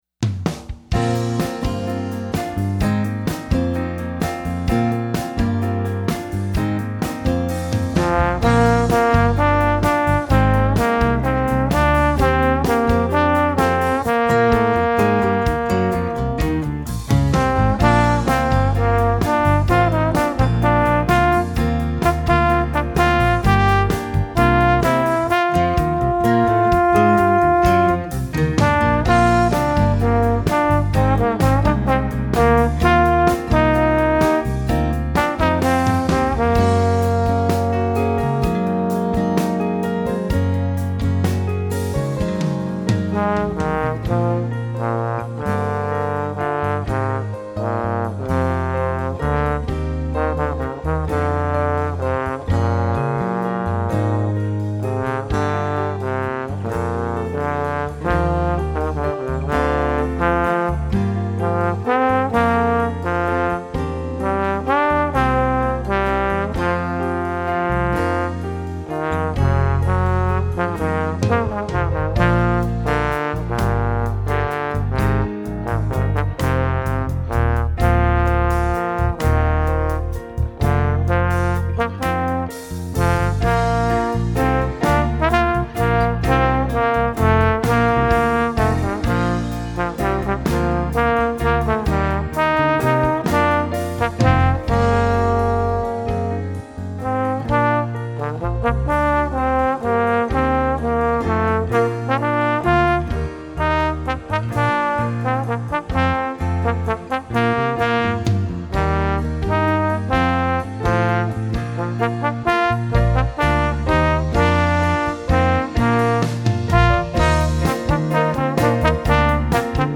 Voicing: Trombone w/enh